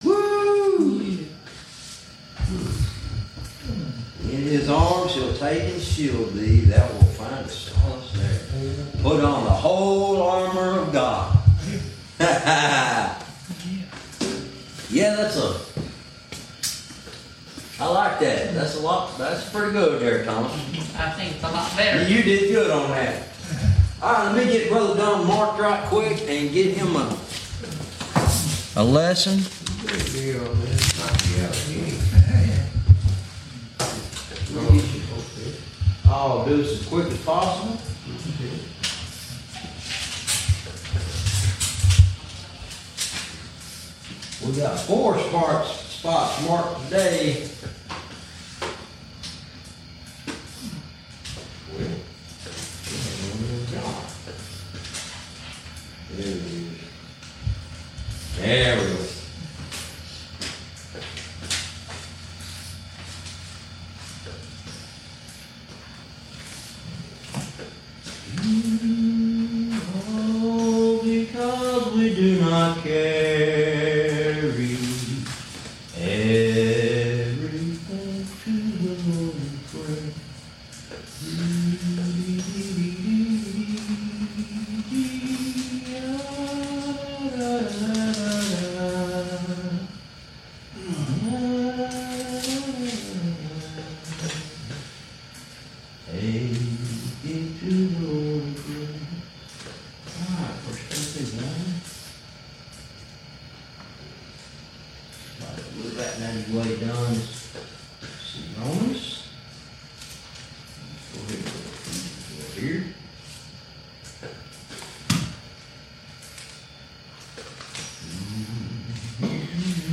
Verse by verse teaching - Lesson 32